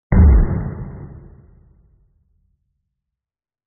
explosion2.mp3